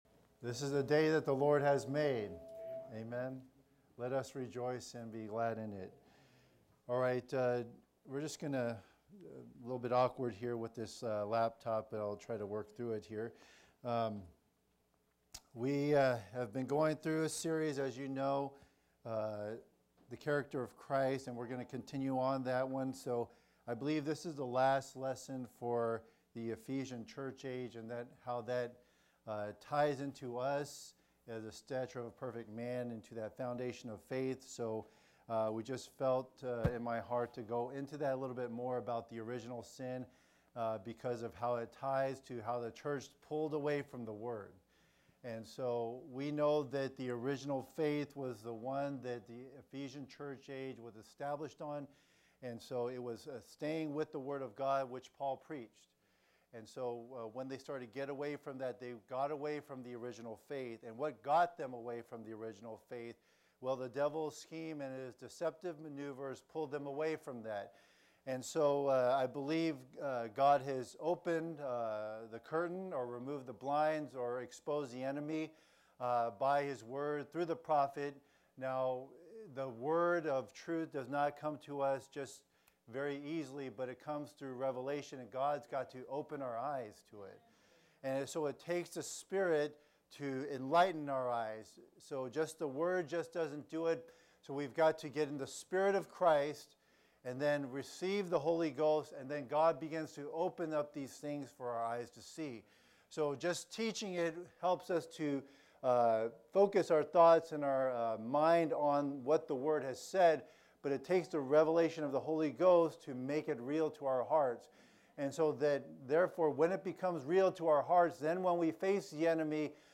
Series: Sunday school , The Character of Christ